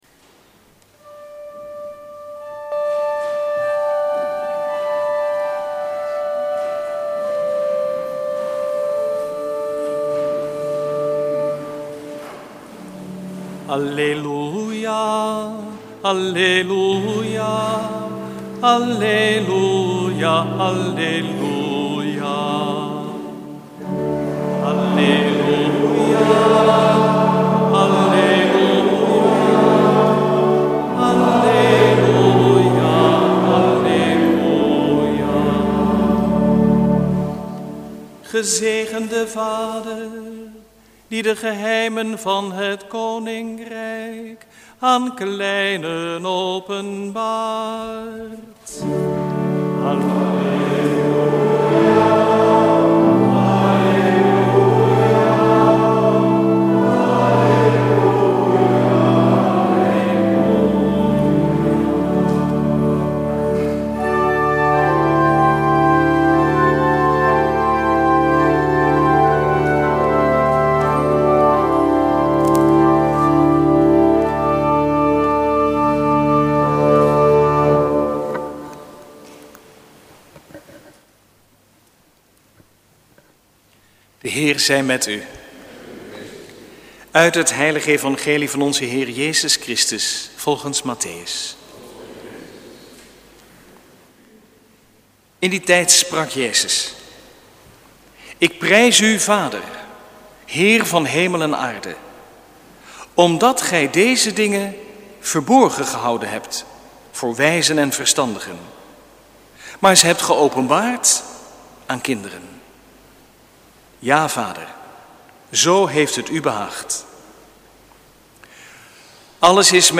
Preek 14e zondag, door het jaar A, 3 juli 2011 | Hagenpreken